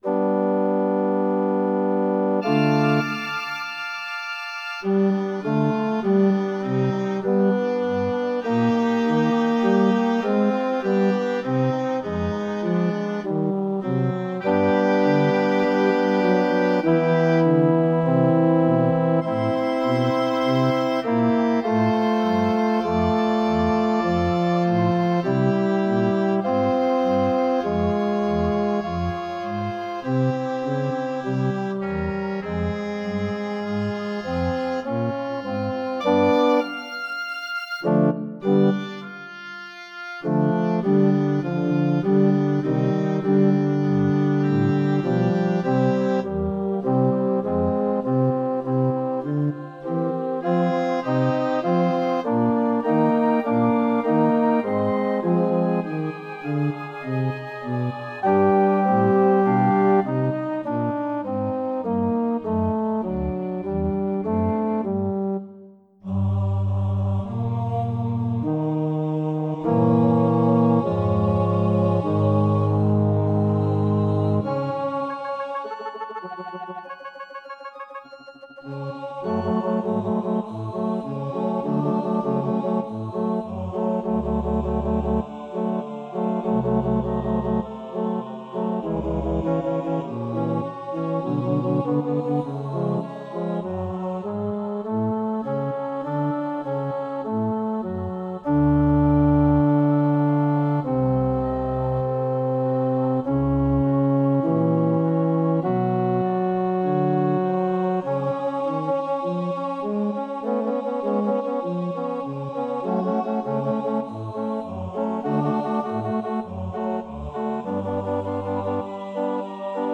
Mp3 files are provided for each movement, with each voice part emphasised, or with all voice parts at the same level.